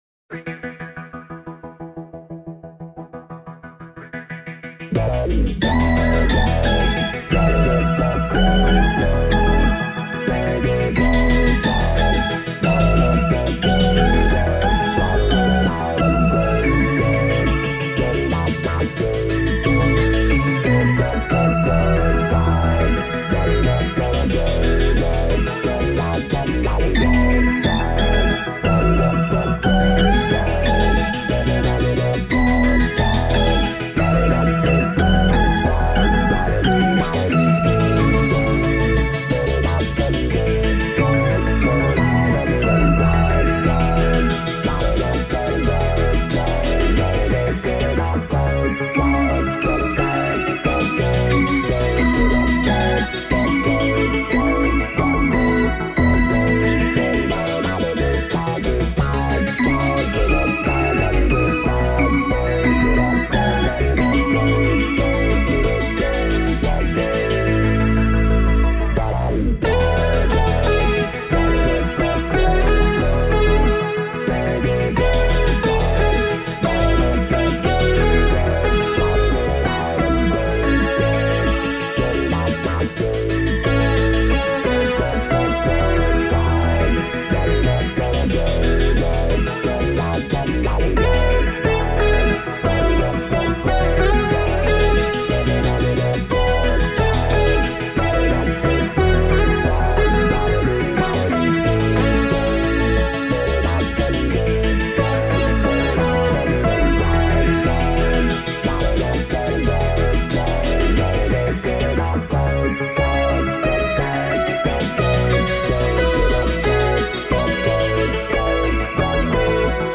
I also recorded a demo track that uses the Boss SY-300 for everything, except for the drum tracks: